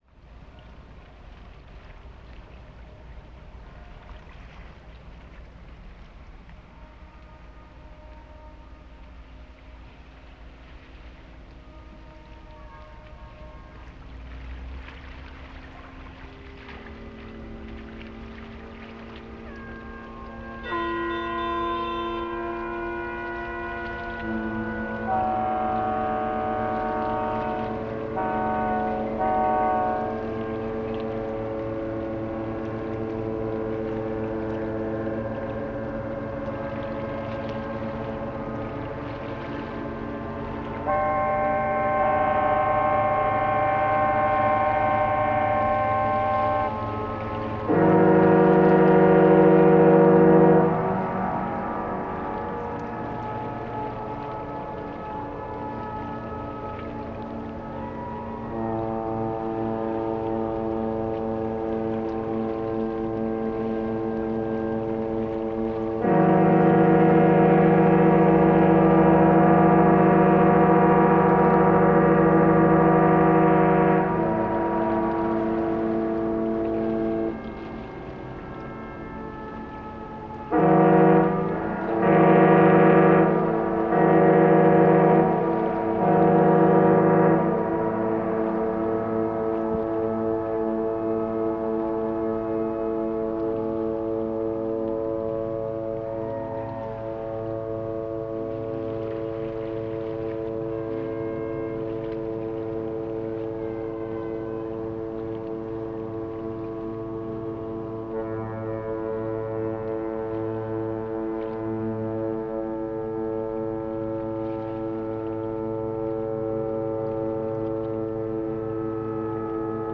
New Year's Eve, Vancouver harbour, 1980-81, from The Vancouver Soundscape